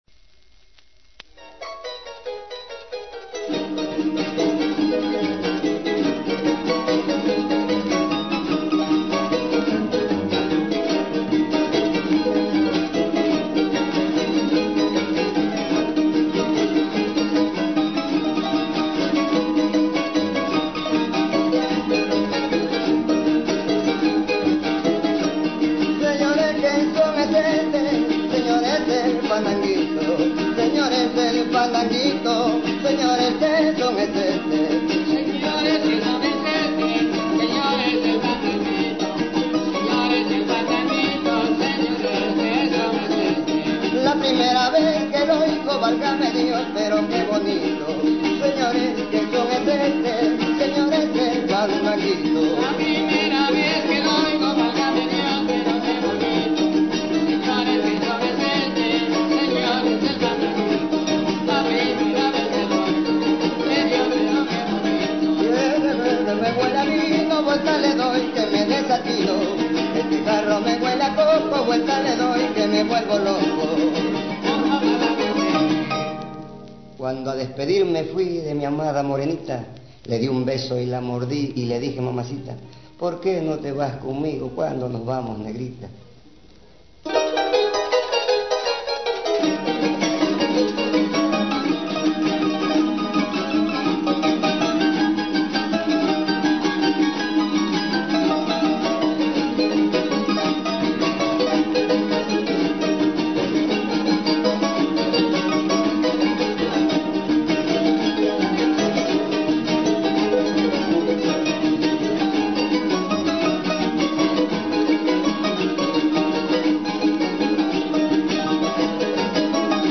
Grabado en Tlacotalpan, con dos
"fieras" del son jarocho.